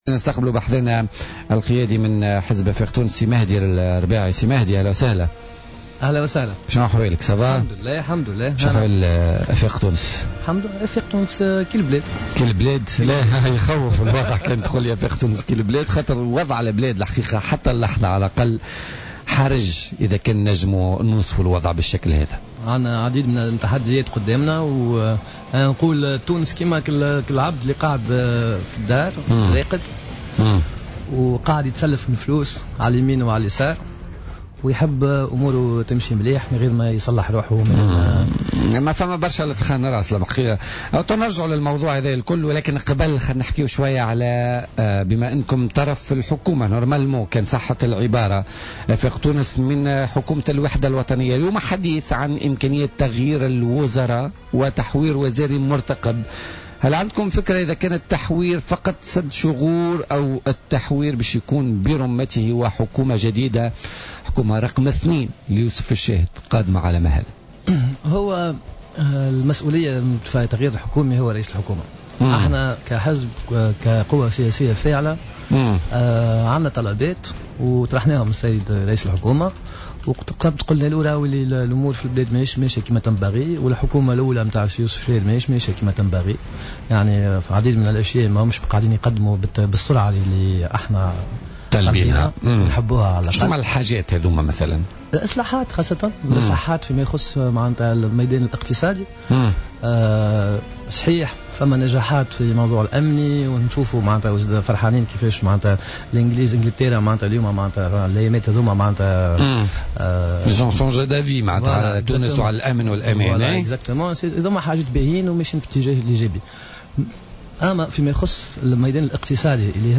ضيف "بوليتيكا" اليوم الاثنين